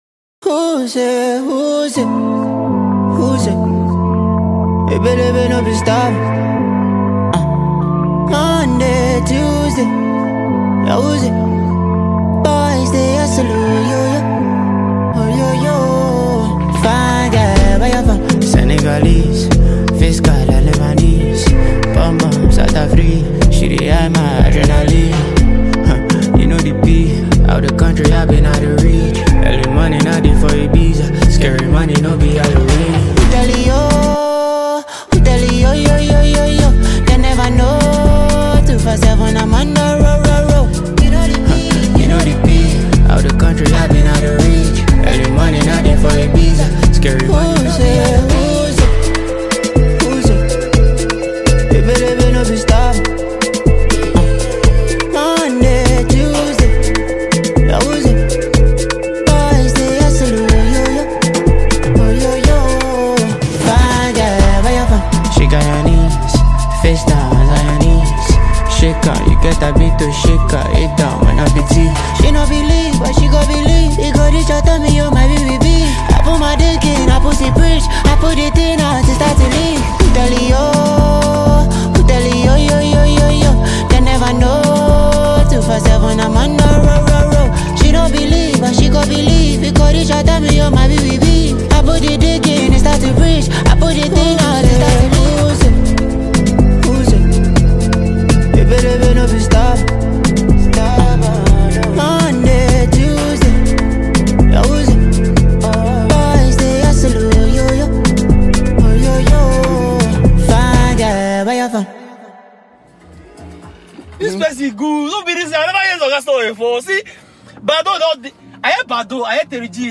From smooth melodies to hard-hitting rhythms